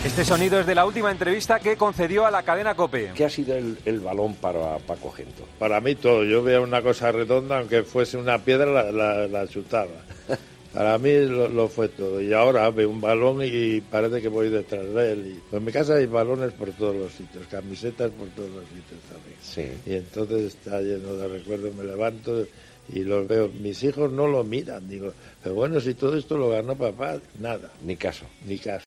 Gento, en su última entrevista en COPE: "El balón fue todo, aunque viera una piedra redonda, la chutaba"